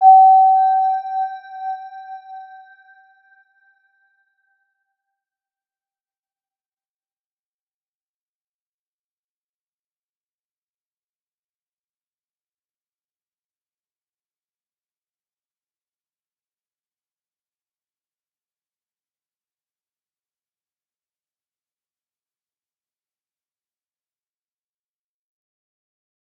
Round-Bell-G5-mf.wav